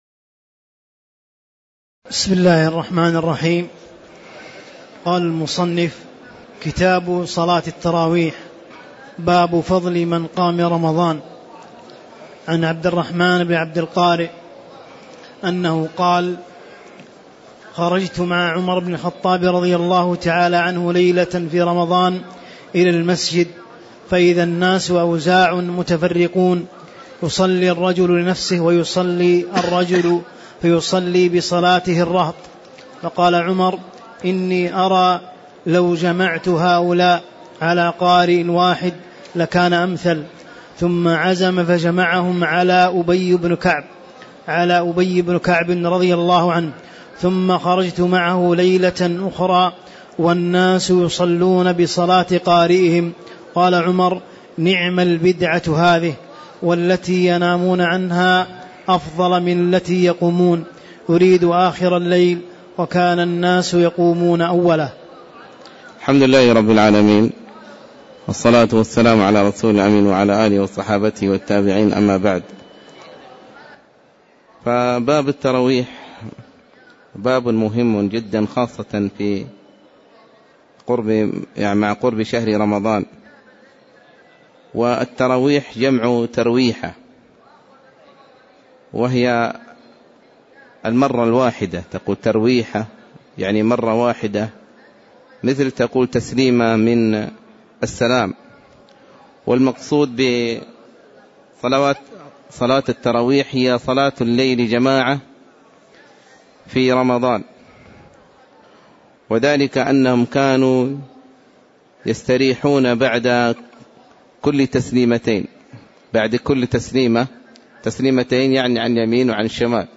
تاريخ النشر ٢٢ شعبان ١٤٣٧ هـ المكان: المسجد النبوي الشيخ